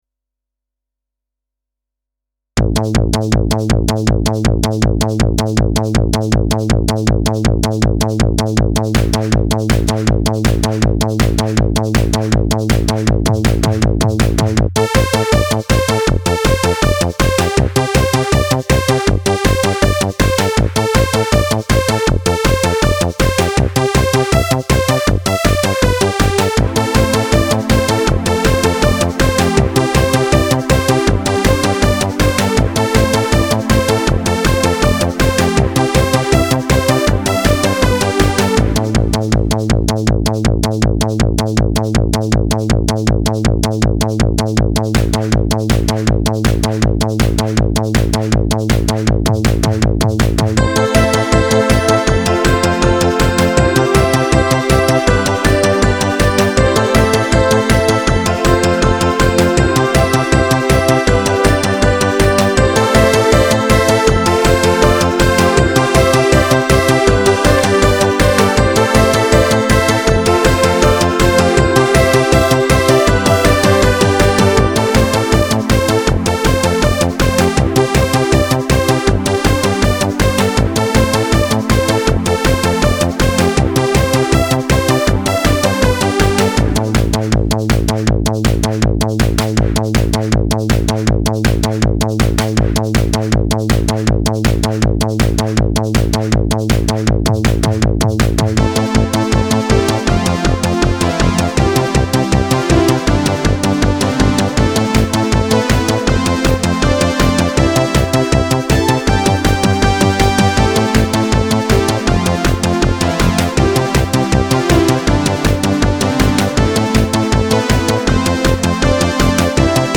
Style: Dance/Trance
A dance track I done